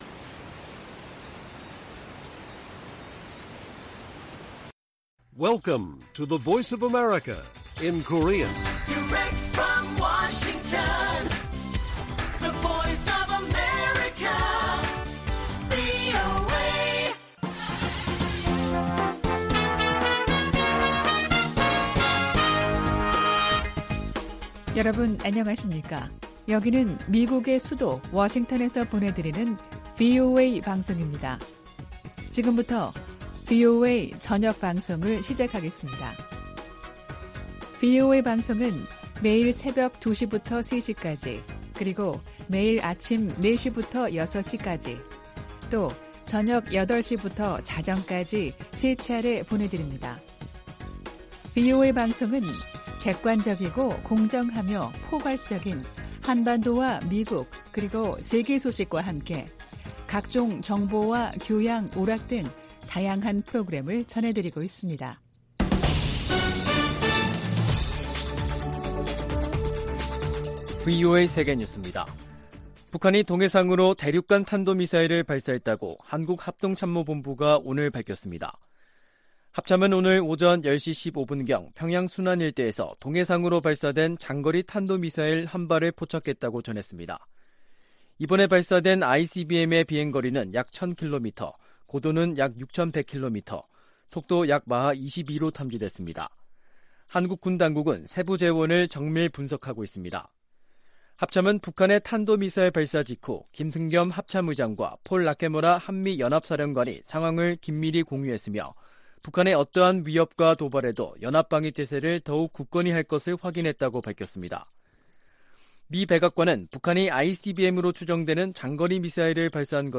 VOA 한국어 간판 뉴스 프로그램 '뉴스 투데이', 2022년 11월 18일 1부 방송입니다. 북한이 오늘, 18일, 신형 대륙간탄도미사일, ICBM인 ‘화성-17형’을 시험발사해 정상비행에 성공한 것으로 알려졌습니다. 한국 합동참모본부는 이에 대응해 F-35A 스텔스 전투기를 동원해 북한 이동식발사대(TEL) 모의표적을 타격하는 훈련을 실시했습니다.